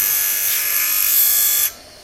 Industrial » Foundry Furnace
描述：Recordings from an actual foundry. Not amazing quality.
标签： foundry industrial iron metal workshop furnace
声道立体声